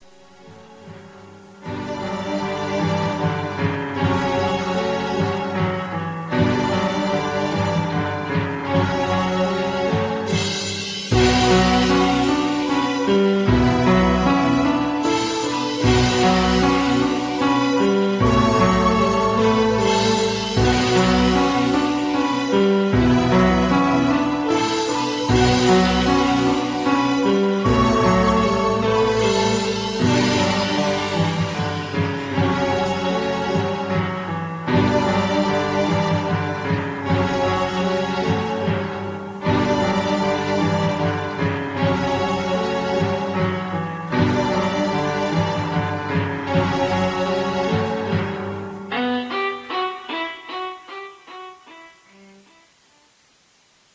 Black Metal Sound Files